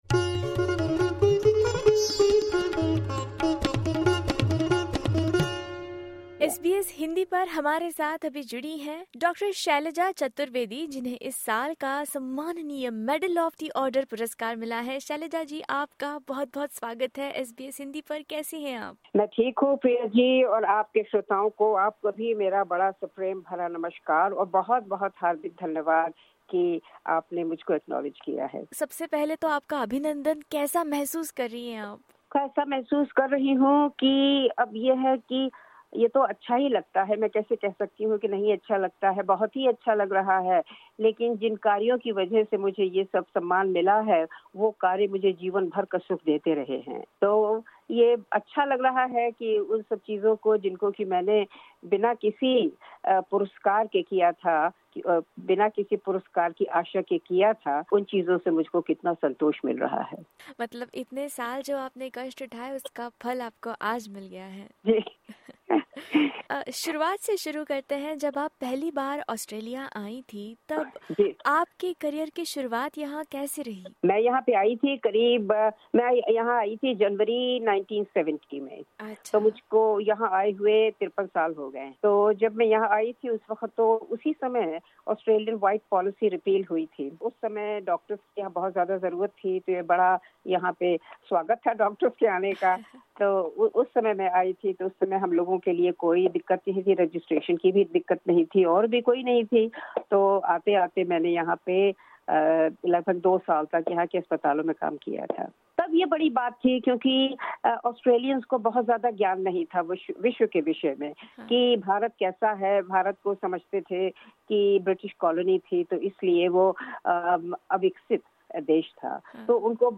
सुनिए एसबीएस हिंदी के साथ हुई उनकी खास बातचीत।